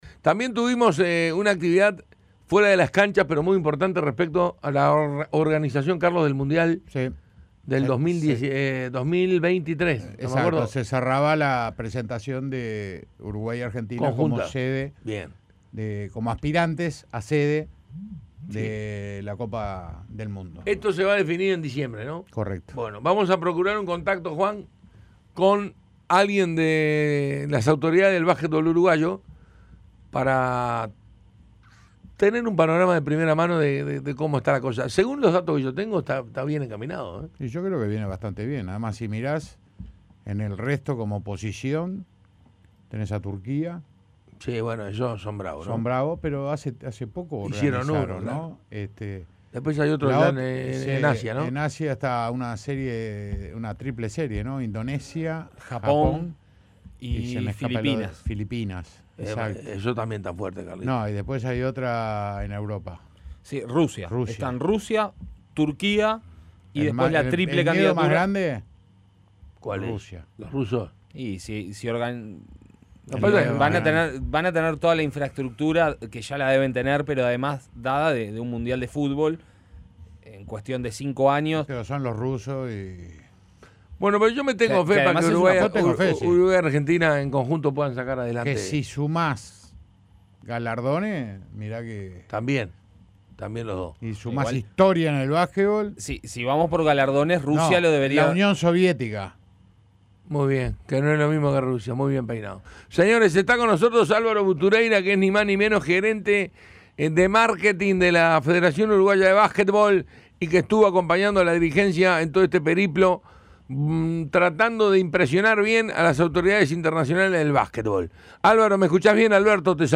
conversó con el panel de Básquetbol de Primera respecto a la presentación oficial de la candidatura ante FIBA en conjunto con Argentina para organizar el Mundial de Básquetbol de 2023.